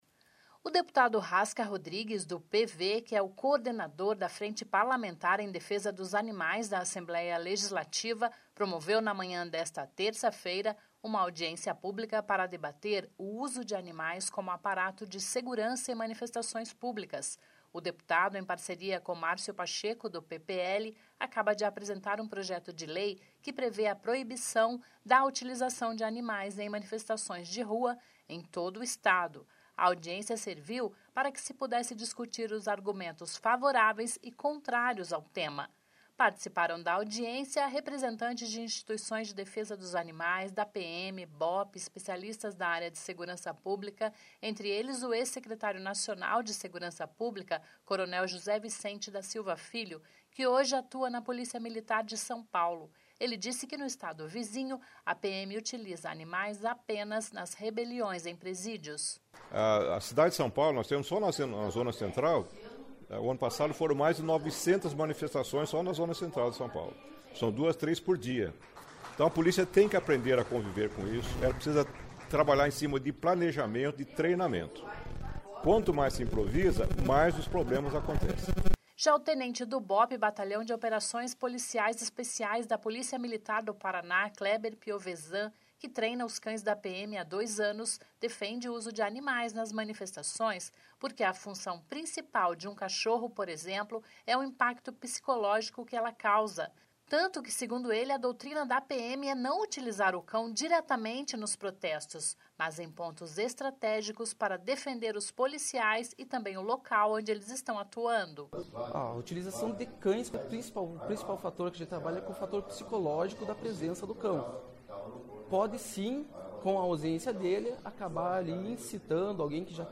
Audiência pública na Assembleia discute uso de animais em manifestações